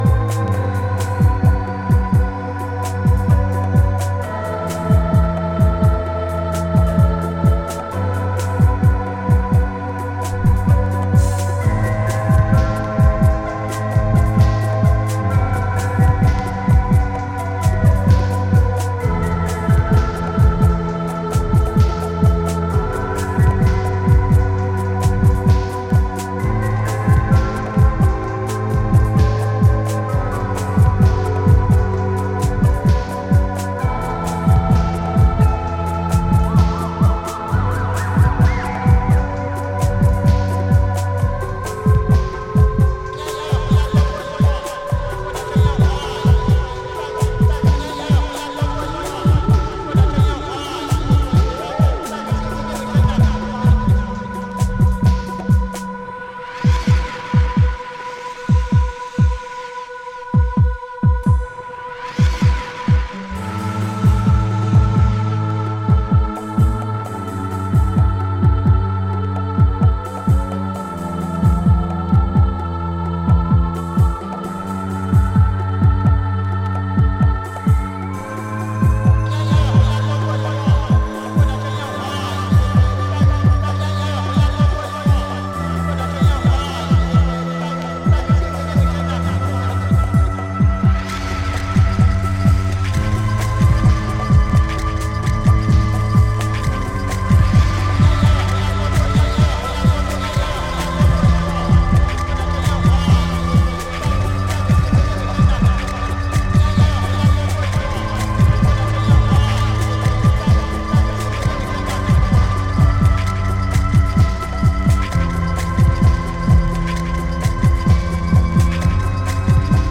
イカツイ低音が太々しく存在を主張したスーパーヘヴィ級グルーヴ